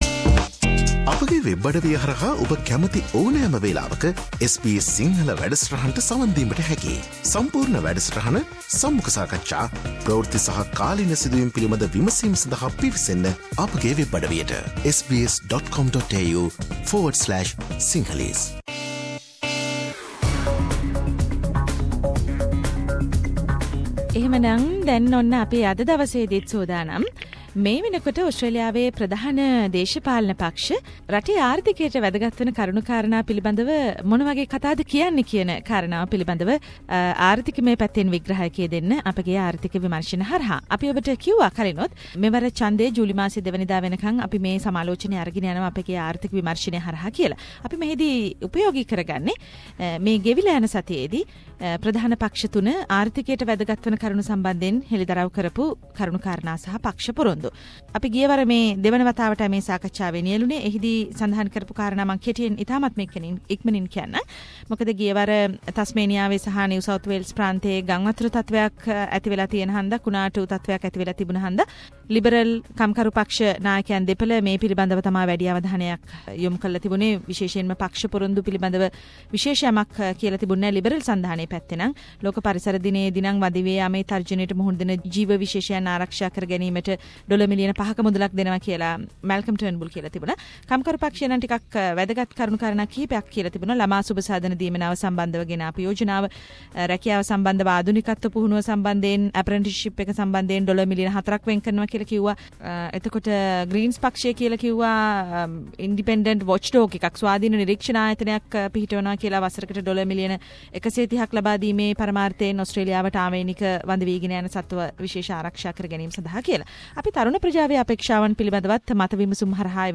A comparative discussion about 3 main party elections promises and future Australian Economy with economic reviewer